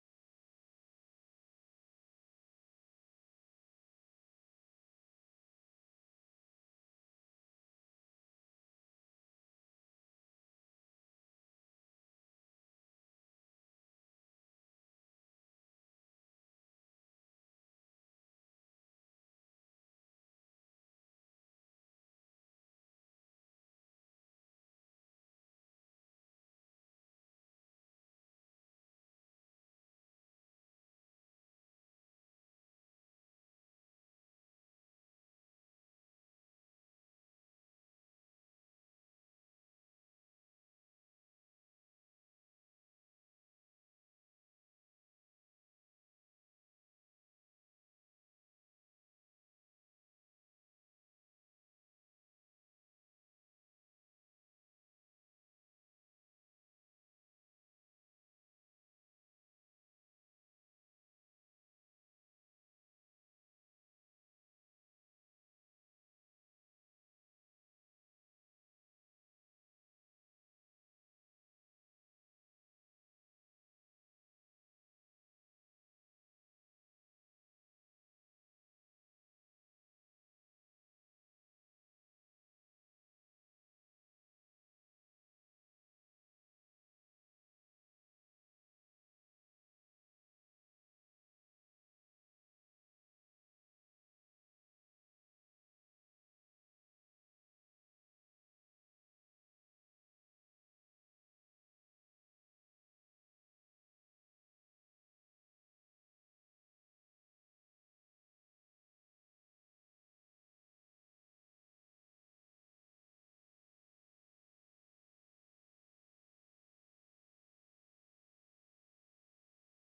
مجلس العزاء